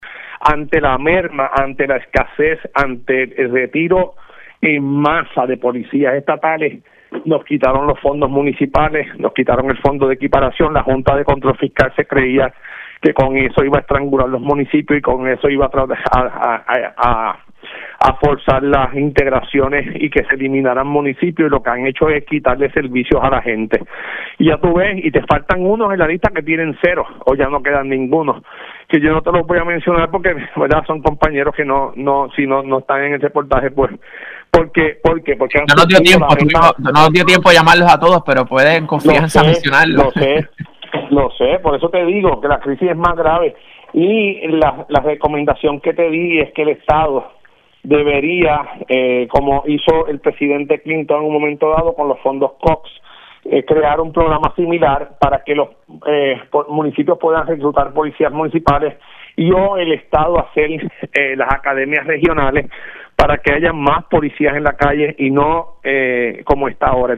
306-JUAN-CARLOS-GARCIA-PADILLA-ALC-COAMO-PROPONE-QUE-MUNICIPIOS-RECLUTEN-POLICIAS-Y-LA-POLICIA-PAGUE-ACADEMIAS.mp3